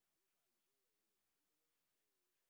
sp07_street_snr30.wav